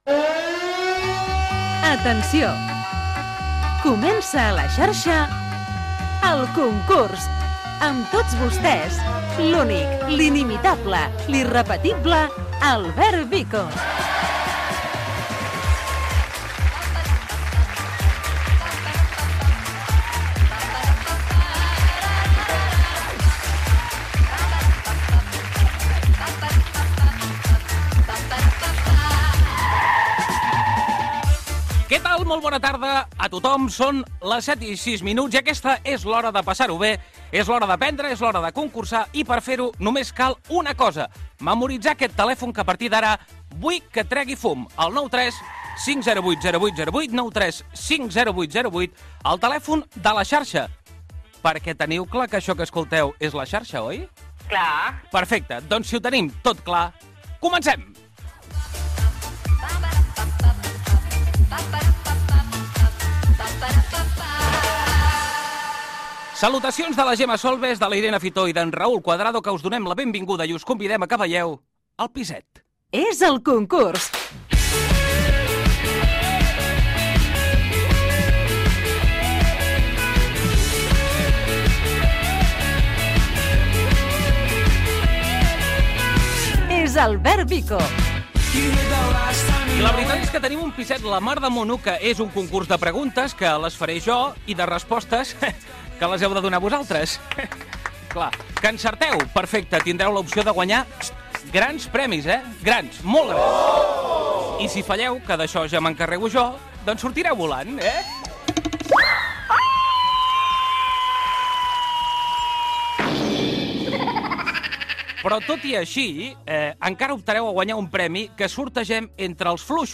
Inici i crèdits del programa Gènere radiofònic Participació